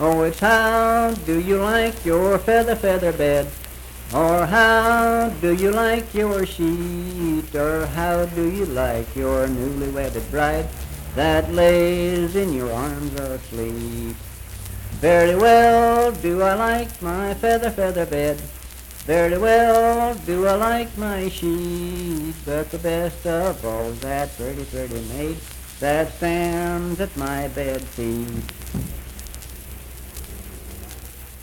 Unaccompanied vocal music performance
Verse-refrain 2(4).
Voice (sung)
Calhoun County (W. Va.)